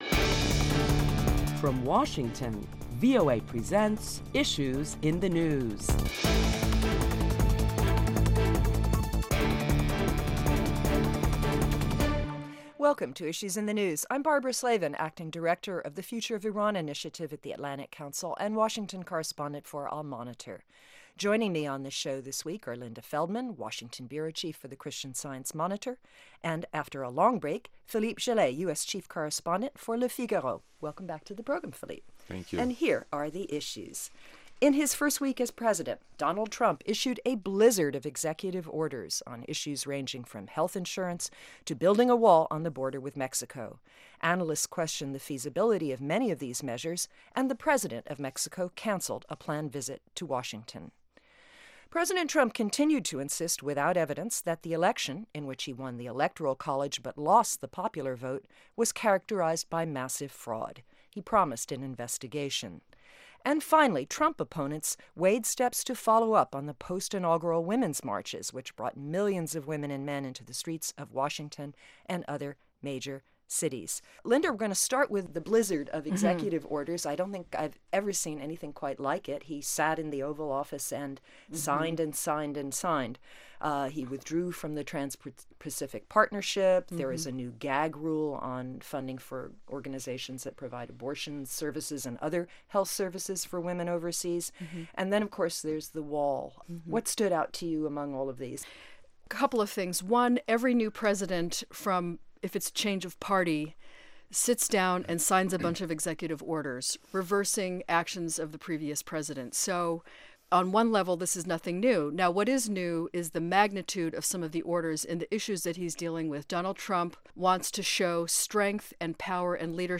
Prominent Washington correspondents discuss topics making headlines around the world.